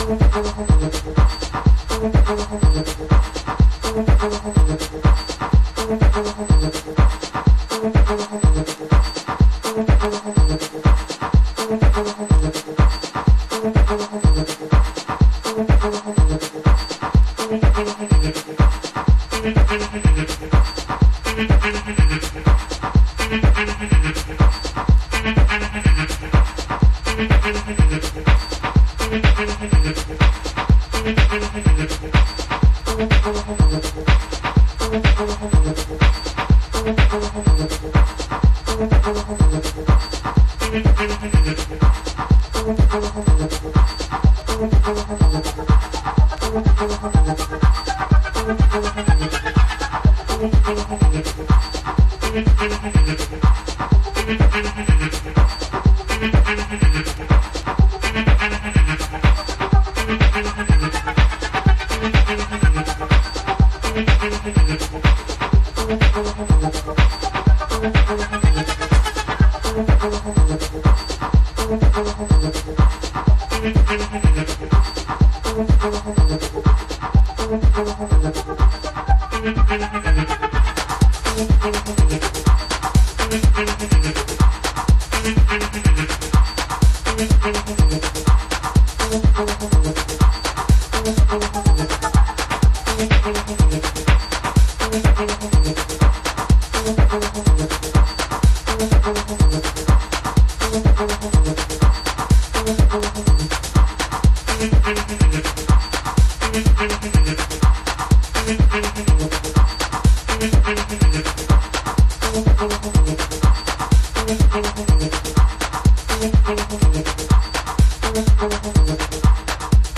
House / Techno
硬質なビートで疾走、ひたすら地を這うようなテクノトラックス。